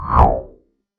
Soft Air Whoosh
A gentle, soft air whoosh with smooth movement and delicate high-frequency content
soft-air-whoosh.mp3